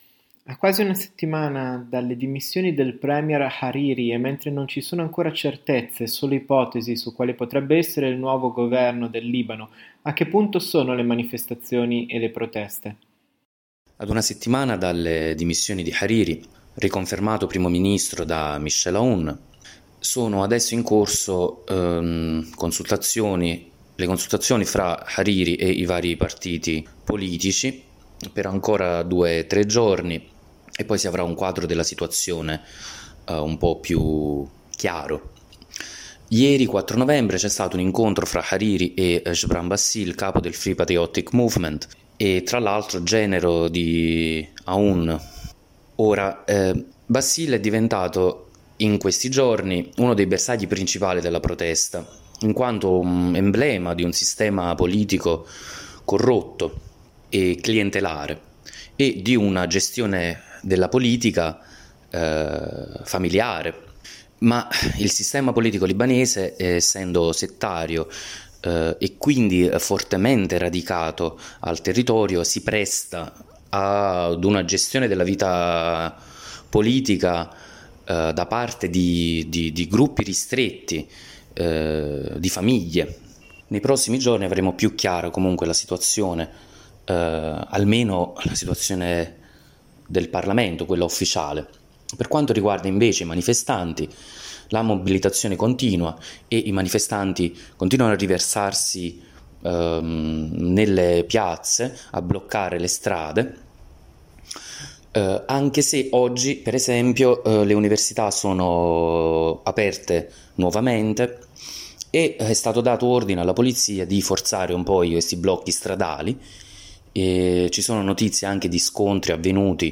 analista politico e giornalista free lance